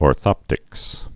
(ôr-thŏptĭks)